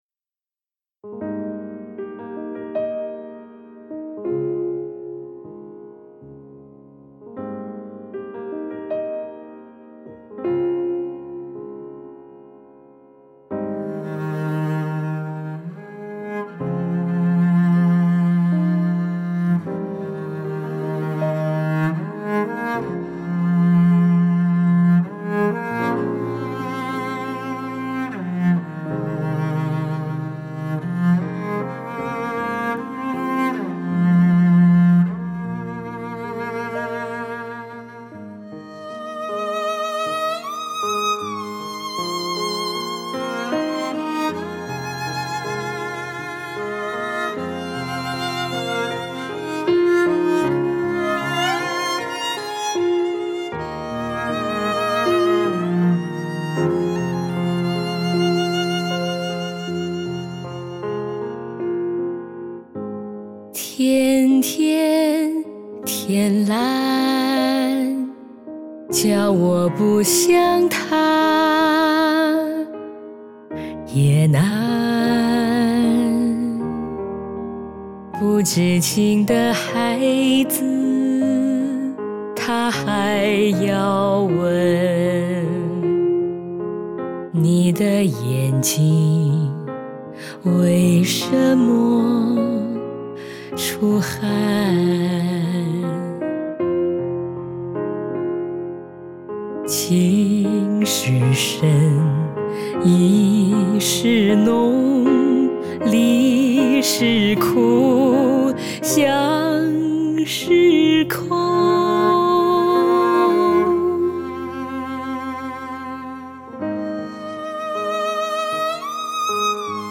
独特醇厚的声音，娓娓珍贵的柔媚味道